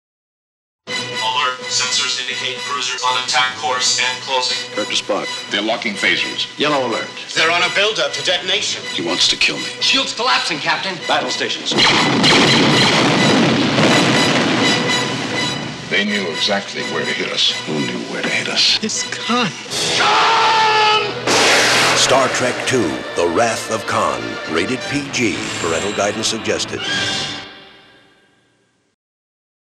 Radio Spots
The spots are very good and capture the action and mood of the movie.